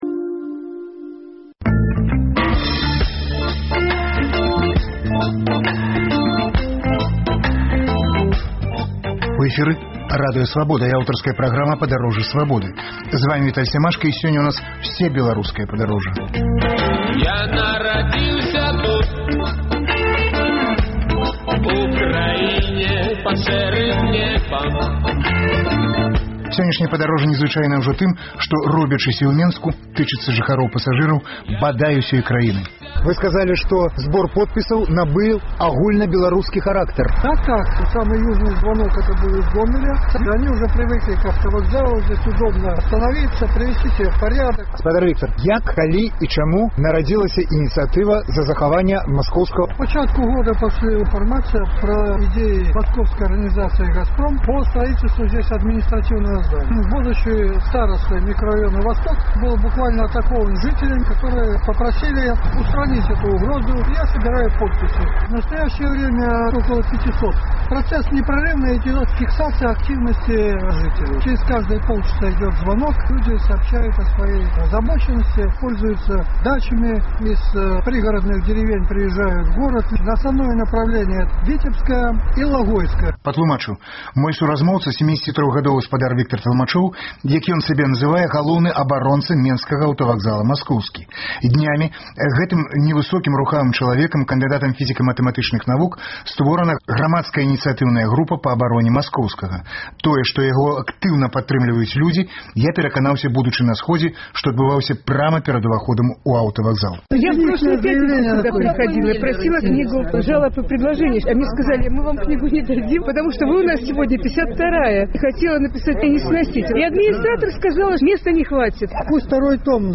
Гэтыя пытаньні абмяркоўвалі ў праскай студыі